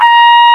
Index of /m8-backup/M8/Samples/FAIRLIGHT CMI IIX/BRASS1